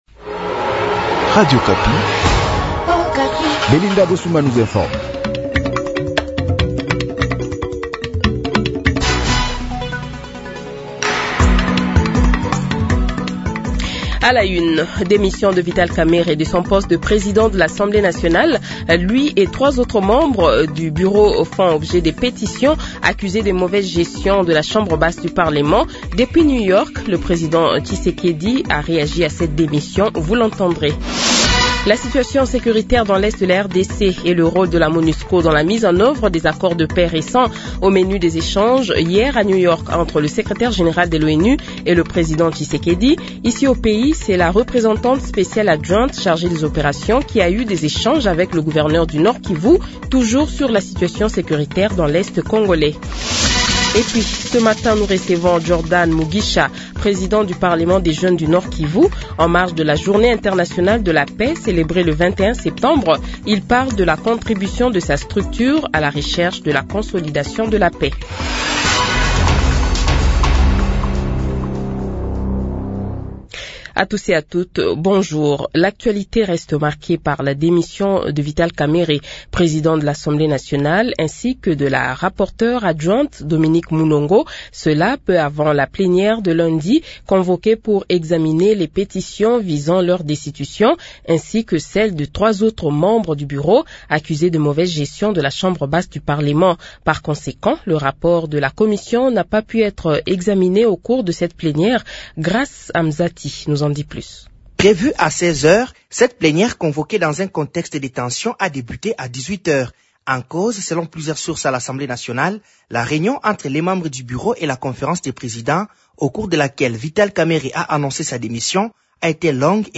Le Journal de 8h, 23 Septembre 2025 :